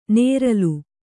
♪ nēralu